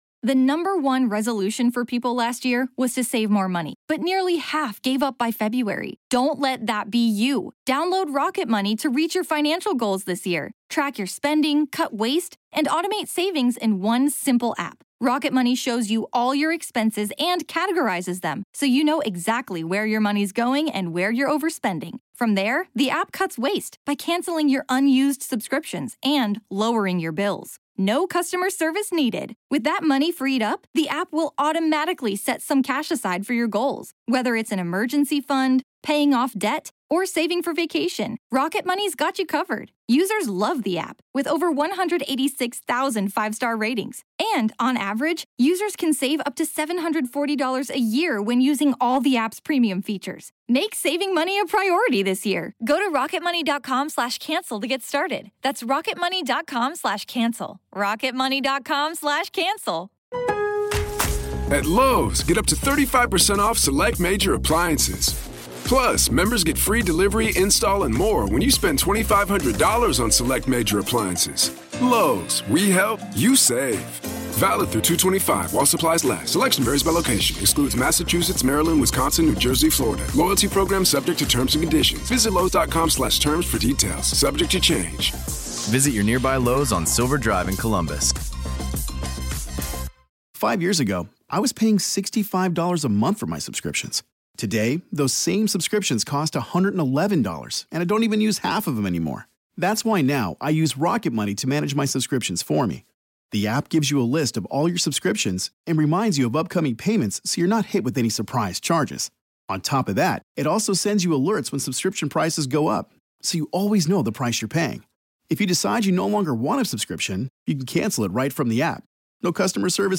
It’s a conversation about connection, compassion, and what can happen when someone is willing to hear voices from the other side.